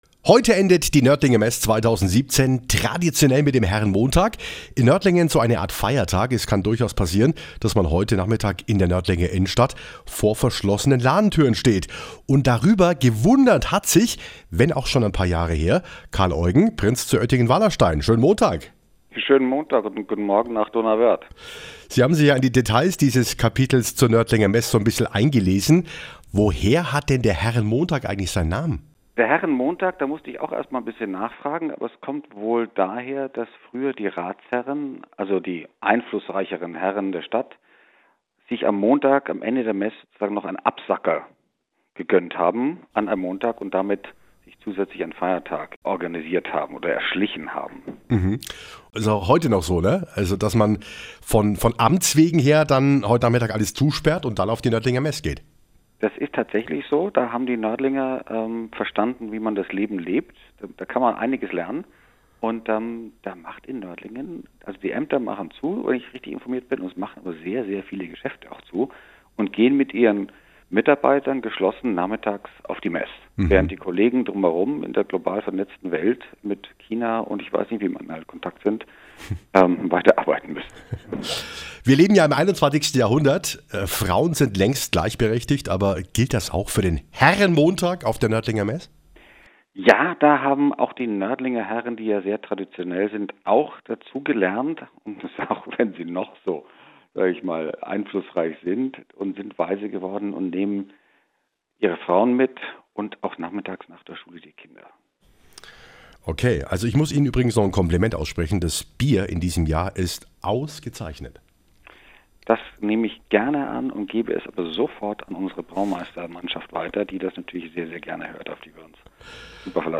Hören Sie hier das Hitradio RT1 Telefoninterview vom 27.6.2017 :